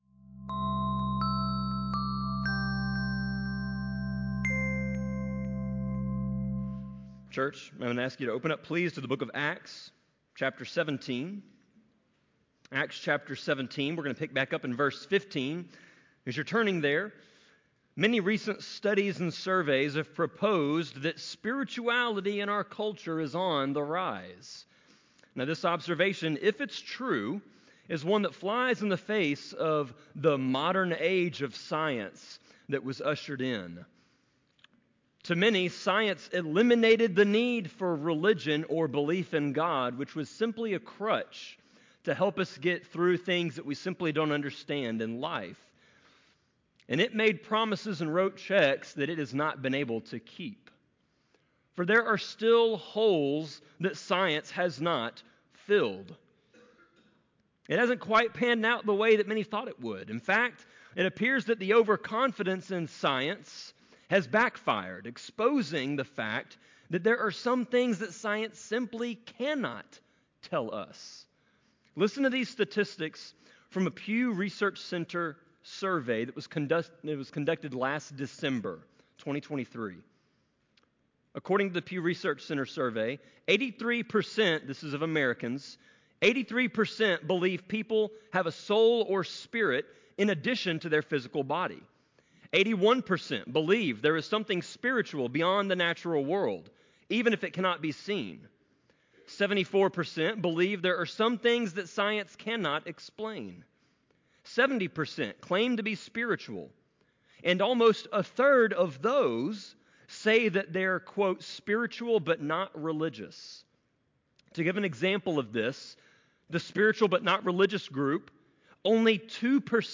Sermon-24.8.4-CD.mp3